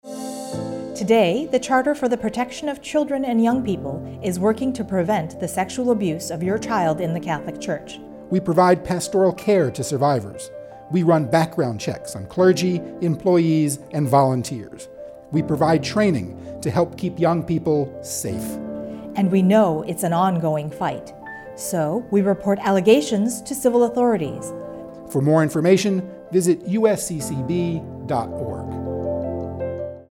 The second of two 30 second audio spots to be run as PSAs on radio in commemoration of the 20th Anniversary of the Charter for the Protection of Children and Young People.